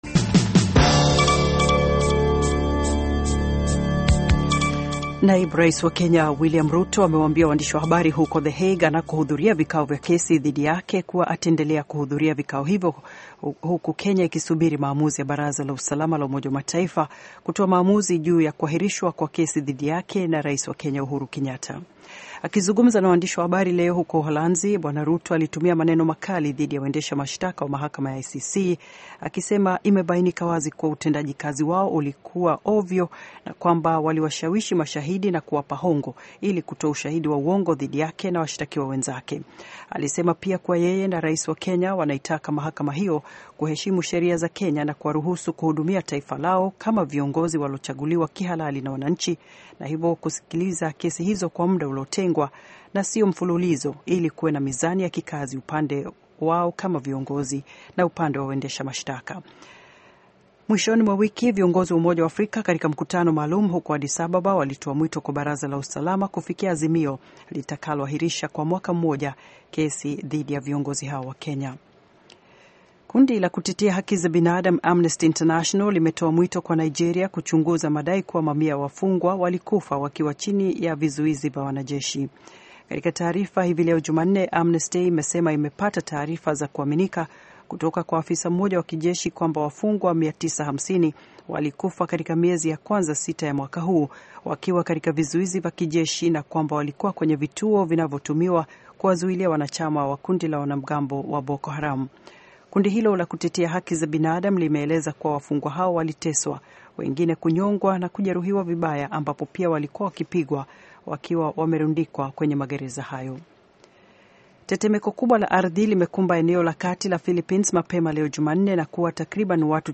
Taarifa ya Habari VOA Swahili - 6:22